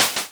Add floor switch sound effect.
floor-switch.wav